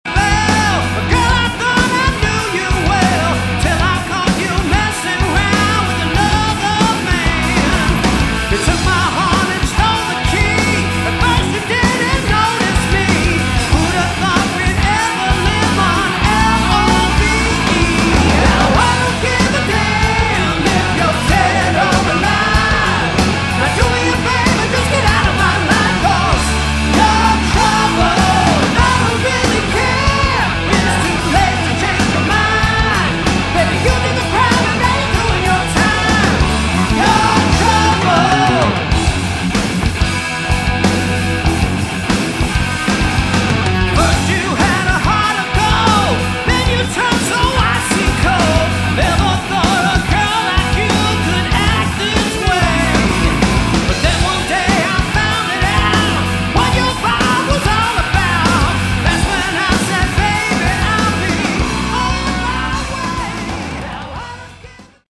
Category: Hard Rock
Vocals
All Guitars
Bass
Keyboards
Drums & Backing Vocals